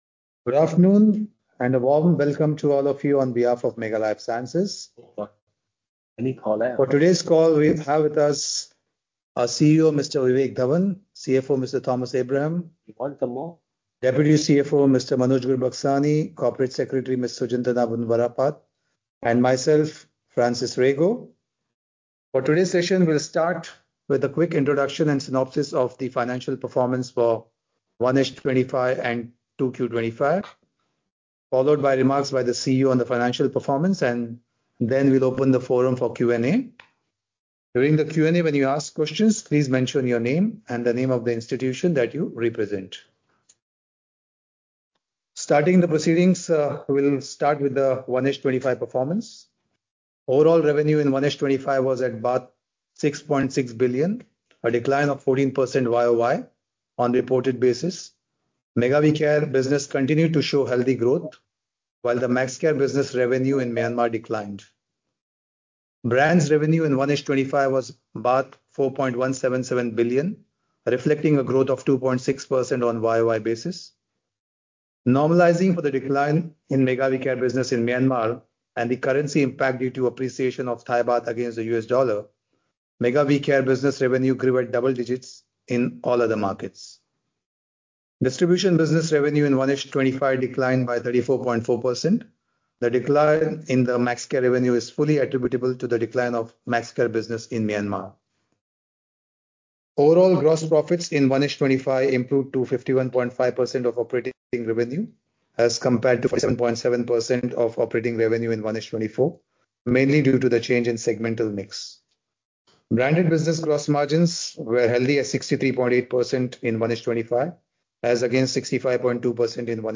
2q25-earnings-conference-call.mp3